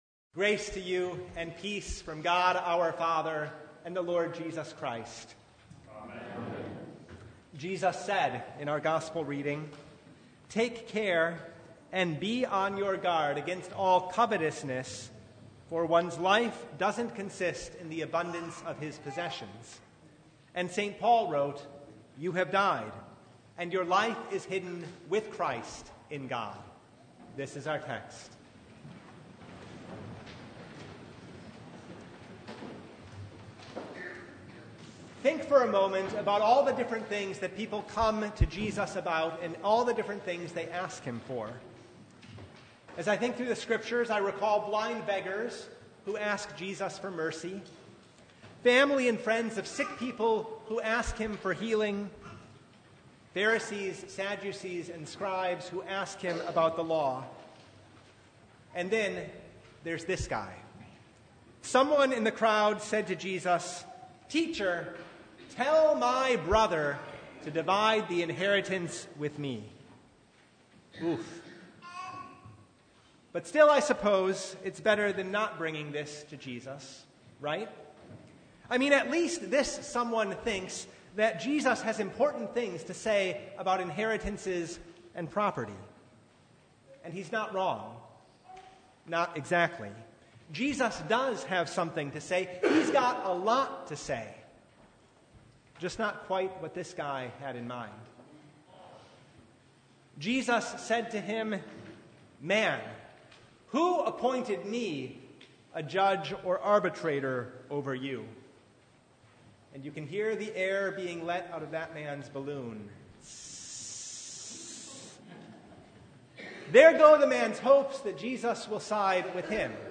Service Type: Sunday
Download Files Notes Topics: Sermon Only « “Lord, Teach Us To Pray” Fed, Clothed, and Given the Kingdom!